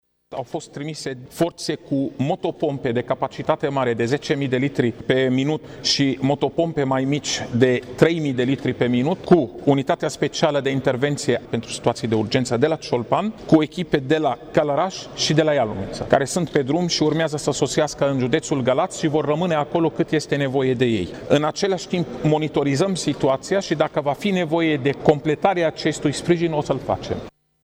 Prefectura a cerut ajutorul militarilor pentru a interveni la nevoie şi autorităţile de la Bucureşti au decis în urmă cu puţin timp să trimită acolo forţe speciale – spune secretarul de stat în Ministerul de Interne, Raed Arafat: